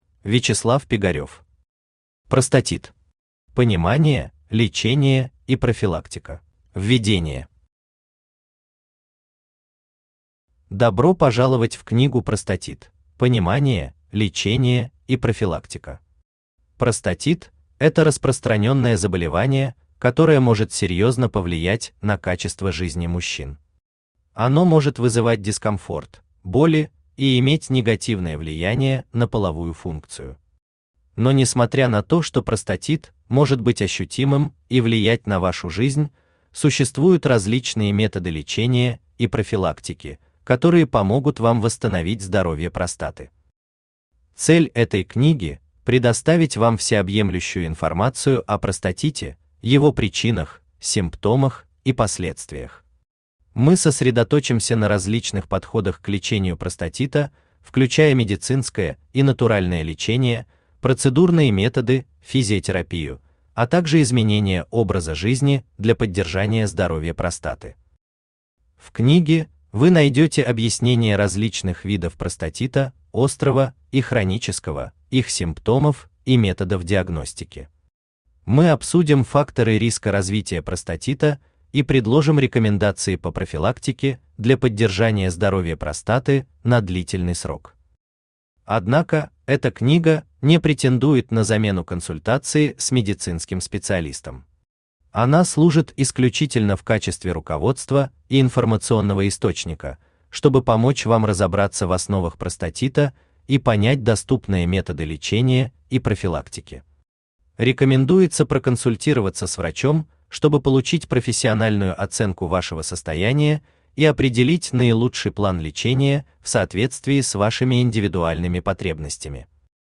Аудиокнига Простатит. Понимание, лечение и профилактика | Библиотека аудиокниг
Понимание, лечение и профилактика Автор Вячеслав Пигарев Читает аудиокнигу Авточтец ЛитРес.